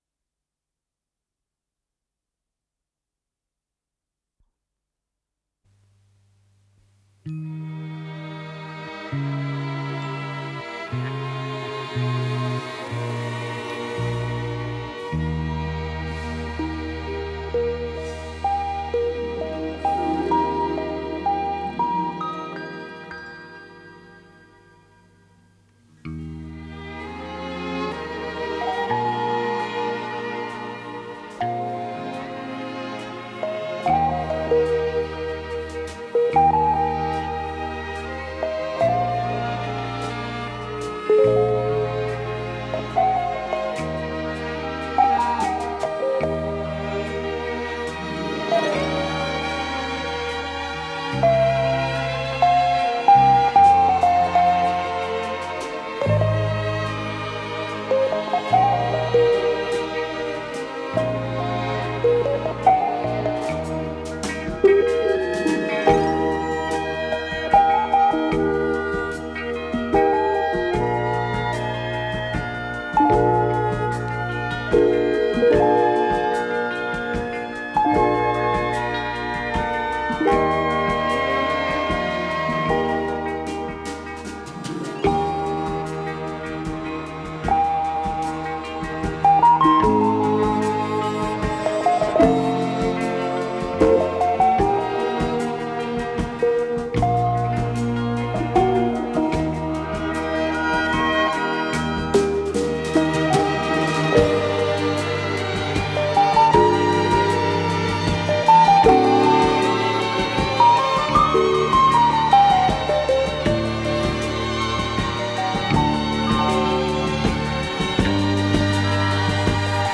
с профессиональной магнитной ленты
ПодзаголовокДля эстрадного оркестра, ми бемоль мажор
ВариантДубль моно